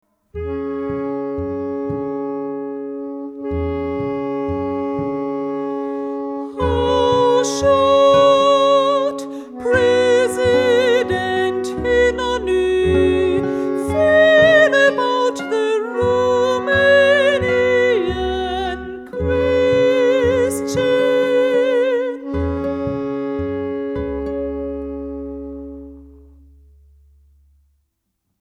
soprano & alto sax
clarinet & bassclarinet
guitar
double bass (T41, T43, T89)